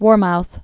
(wôrmouth)